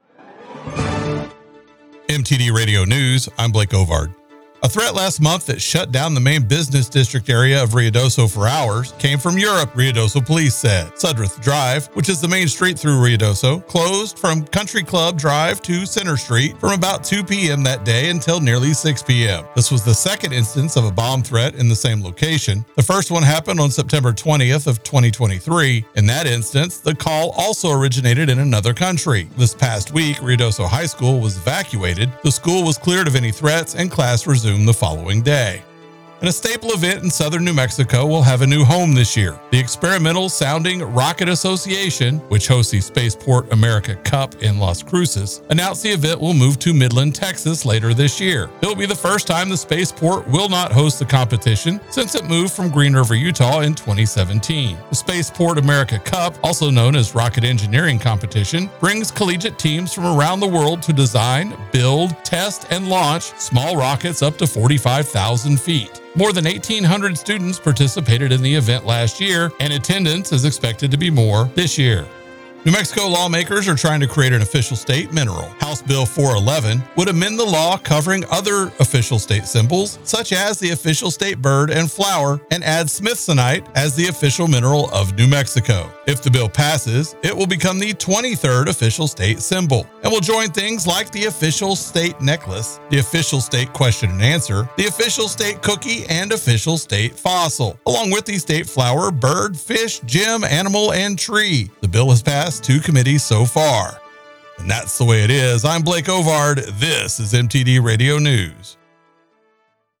KIDX News – Ruidoso and New Mexico